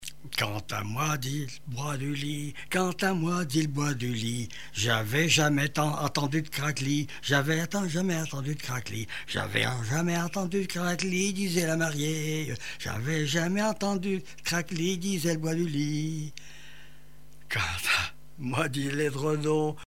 Genre énumérative
Chansons et témoignages
Pièce musicale inédite